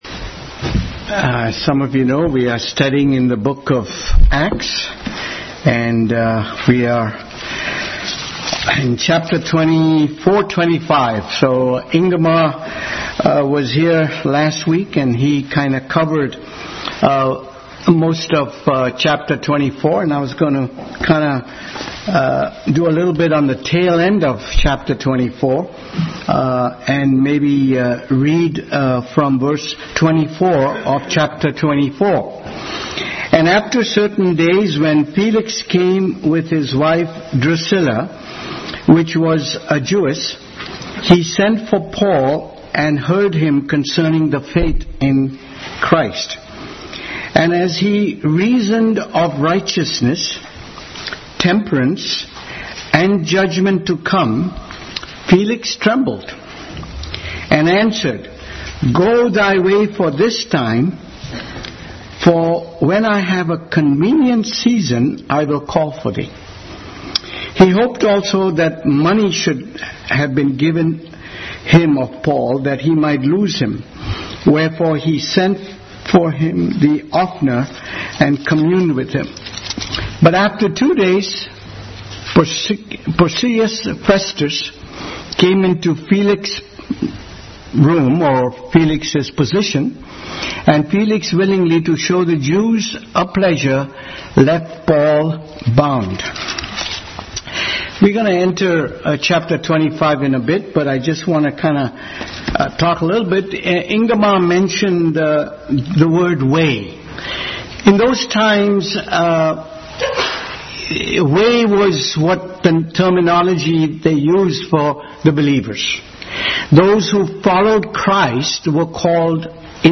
Bible Text: Acts 24:24 – 25:27 | Adult Sunday School study in the book of acts.
25:27 Service Type: Sunday School Bible Text